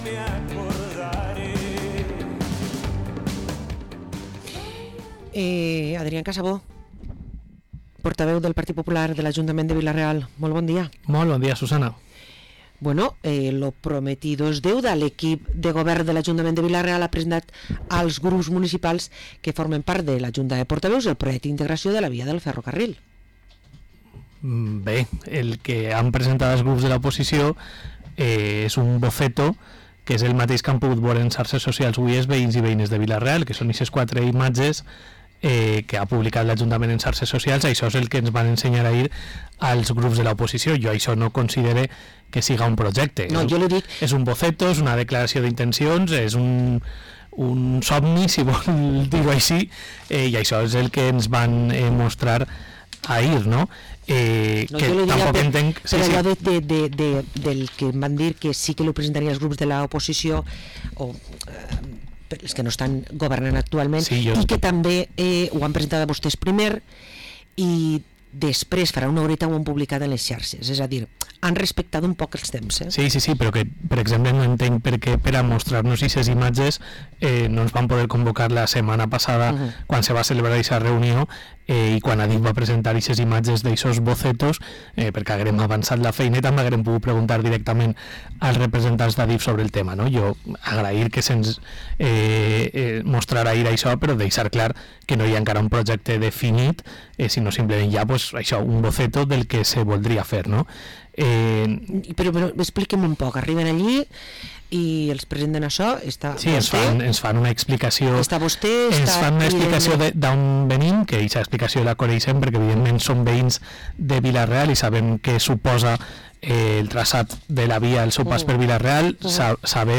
Parlem amb Adrián Casabó Martín, regidor del PP i portaveu a l´Ajuntament de Vila-real